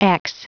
Prononciation du mot ex en anglais (fichier audio)
Prononciation du mot : ex